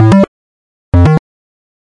基调舞蹈A2 f2 128 bpm
描述：bassline dance a2 f2 128 bpm.wav
Tag: 最小 狂野 房屋 科技 配音 - 工序 贝斯 精神恍惚 舞蹈 俱乐部